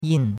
yin4.mp3